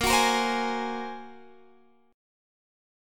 Bb7sus2#5 Chord
Listen to Bb7sus2#5 strummed